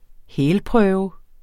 Udtale [ ˈhεːl- ]